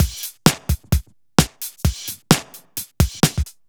Breaks Or House 02.wav